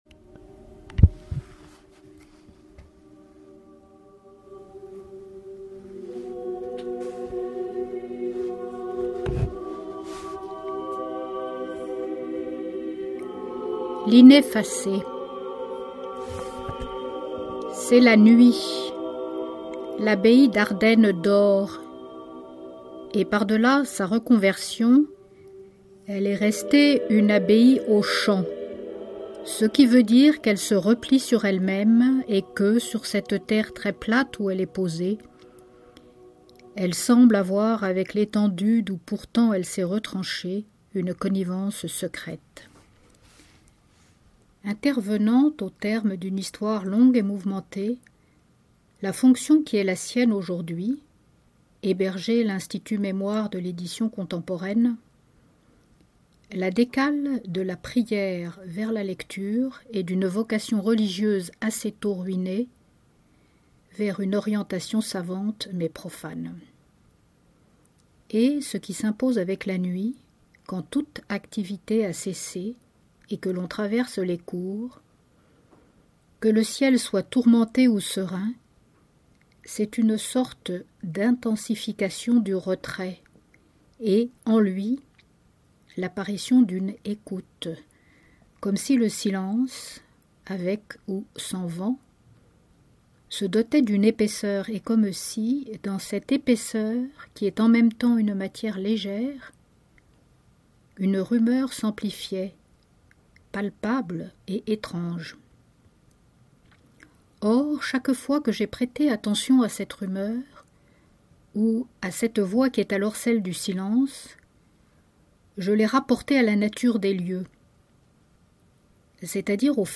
Mais pour l’heure voici une lecture, la première dans une nouvelle rubrique de Poezibao.
En fond musical, "Whispers" de Steven Stucky interprété par l’ensemble Les Métaboles.